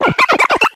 Grito de Popplio.ogg
Grito_de_Popplio.ogg